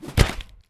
🌲 / foundry13data Data modules soundfxlibrary Combat Single Melee Hit
melee-hit-2.mp3